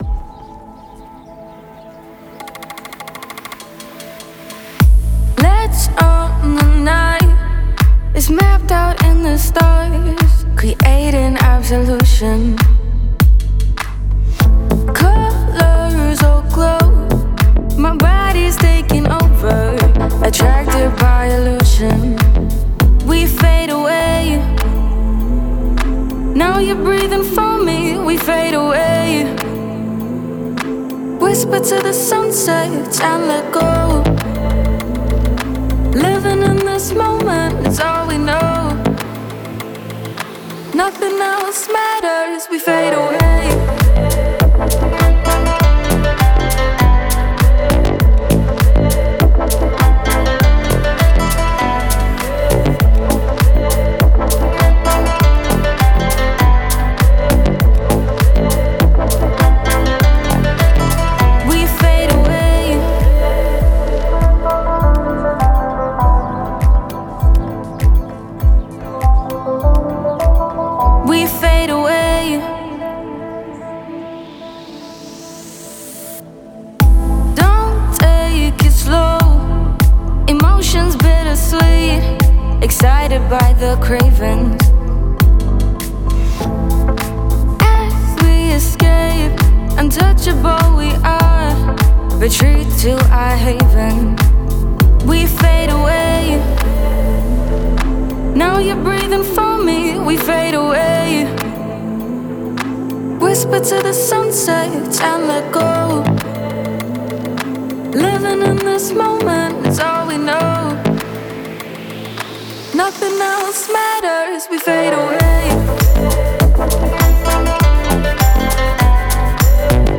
это атмосферная трек в жанре электронной музыки